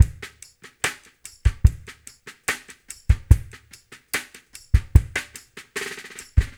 BOL LOFI 2-R.wav